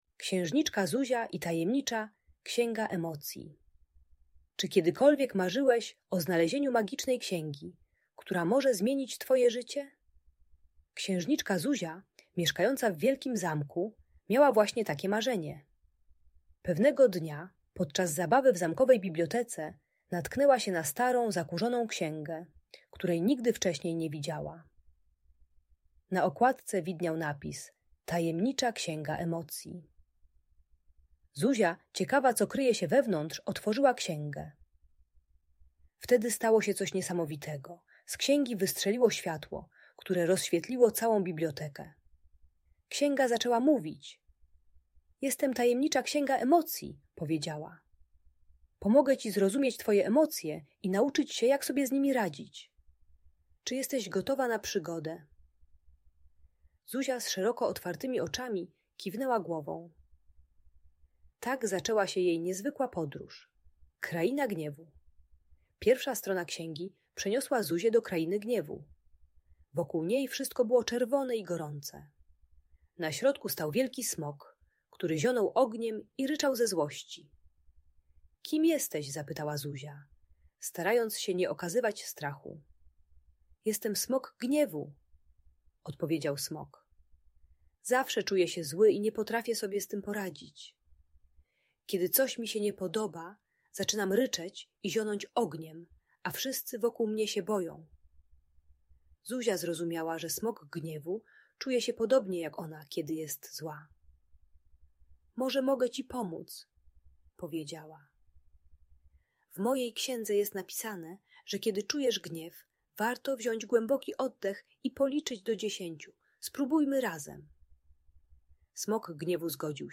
Księżniczka Zuzia i Tajemnicza Księga - Bunt i wybuchy złości | Audiobajka